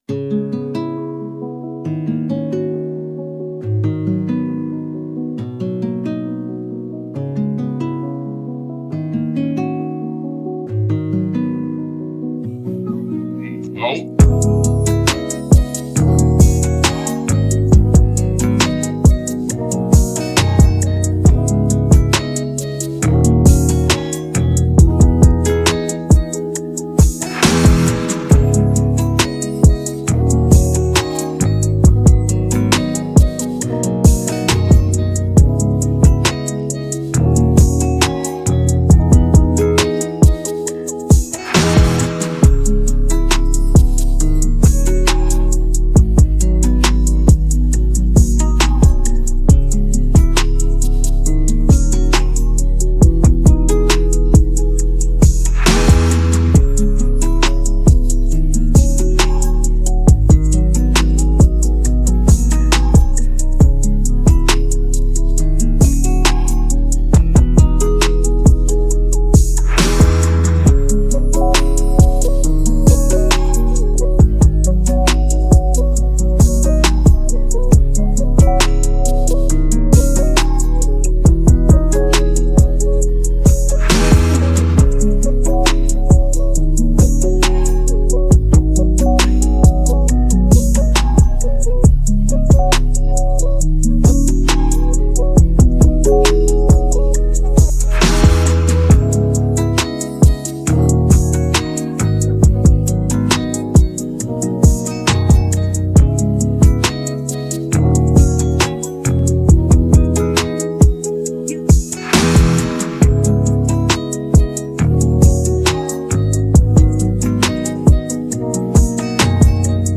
hiphop trap beatsPopR&B